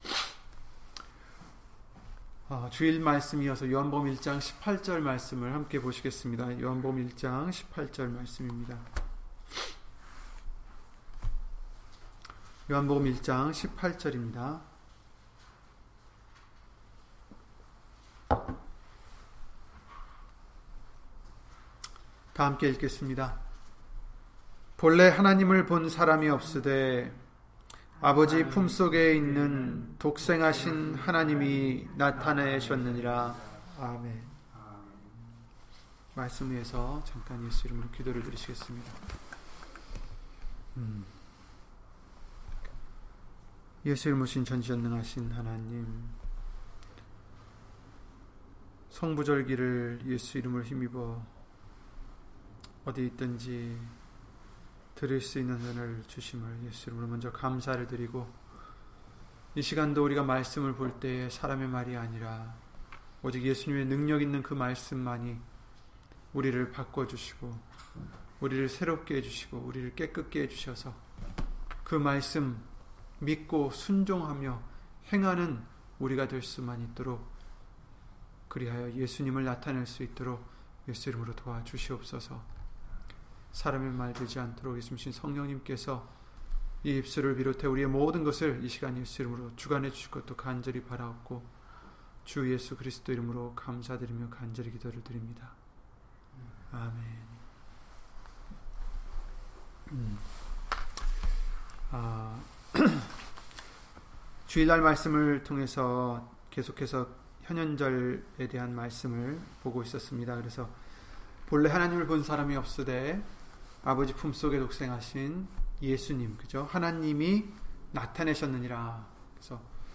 요한복음 1장 18절 [예수님만 나타내자] - 주일/수요예배 설교 - 주 예수 그리스도 이름 예배당